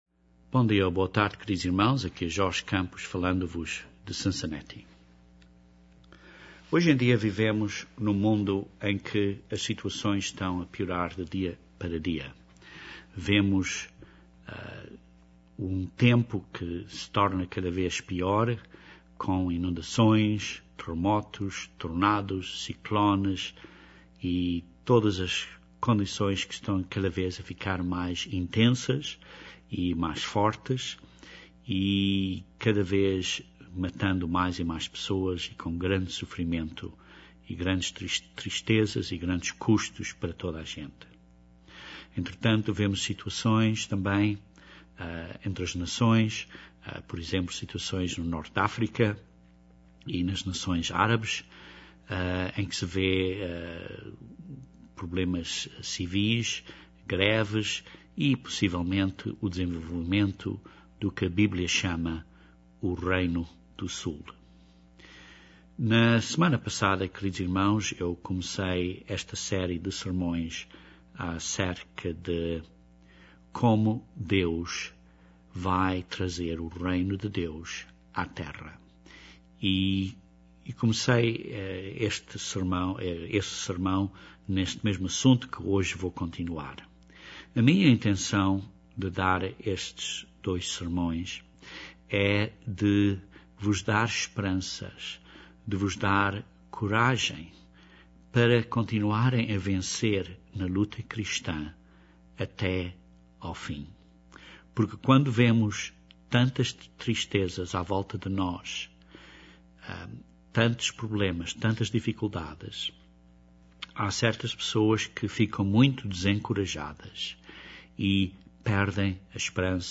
Neste tempo do fim, existem várias condições para vigiarmos, incluindo a queda de valores morais. Este segundo sermão profético nesta série descreve vários acontecimentos até à 2a vinda de Cristo para estabelecer o Reino de Deus na Terra.